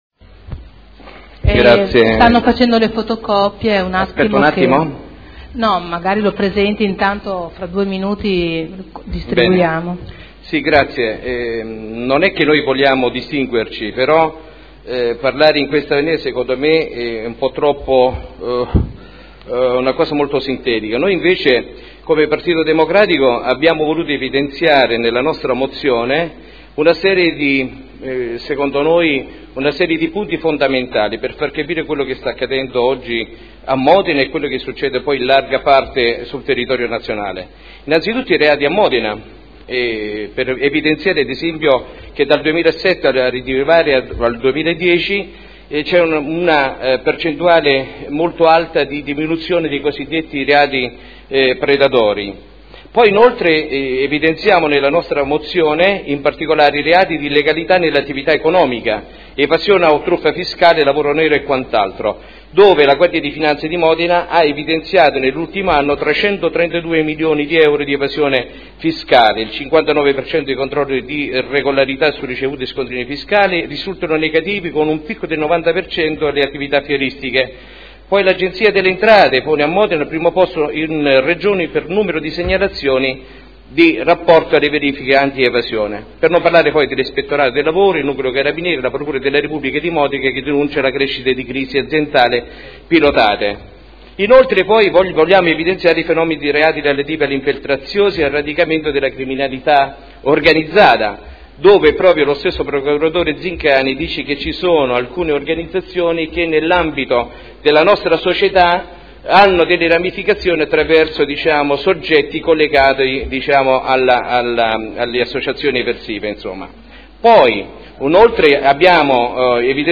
Seduta del 23/05/2011. Ordine del giorno presentato da Dori, Trande: Centrale Operativa comune tra le forze di polizia modenesi.